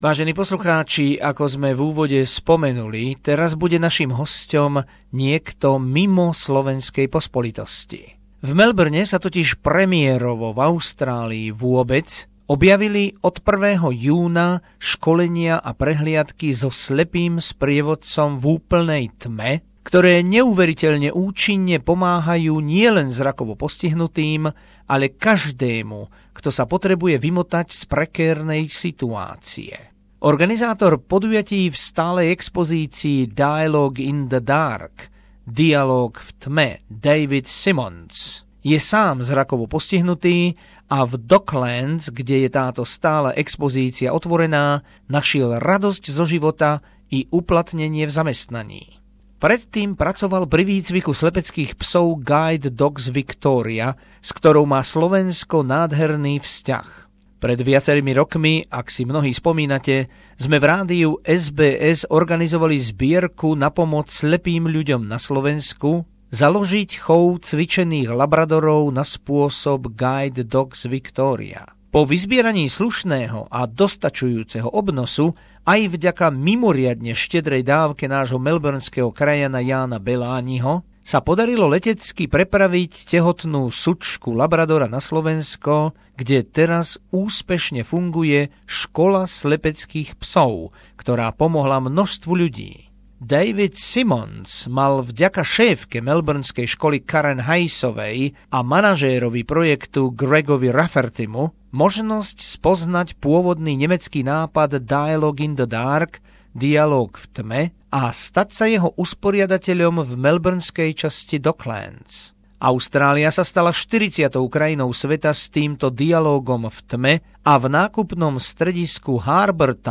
Krátený rozhovor